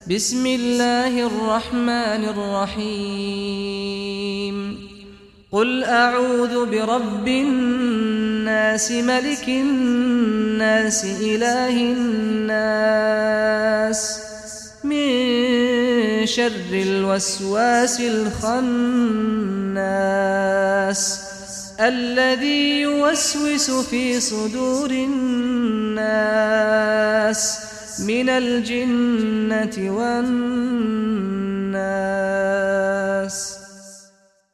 Surah An Nas Recitation by Sheikh Saad Ghamdi
Surah An Nas, listen or play online mp3 tilawat / recitation in Arabic in the beautiful voice of Sheikh Saad al Ghamdi.